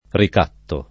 ricatto [ rik # tto ]